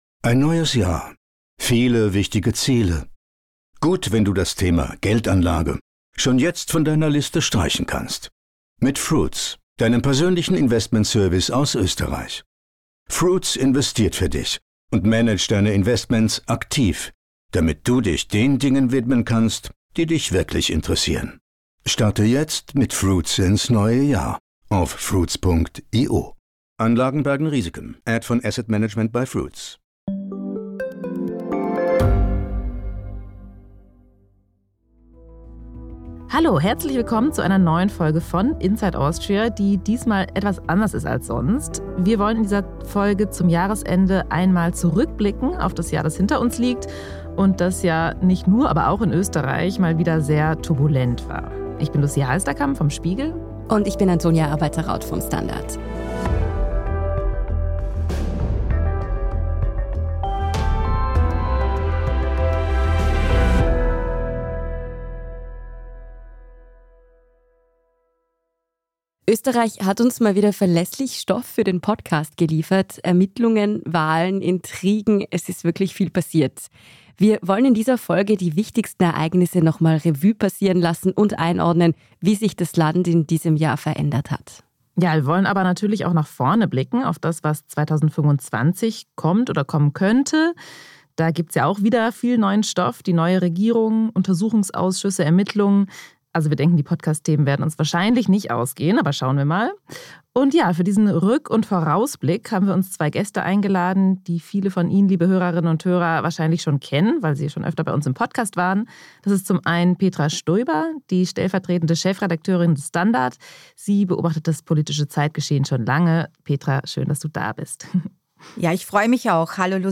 Jahresinterview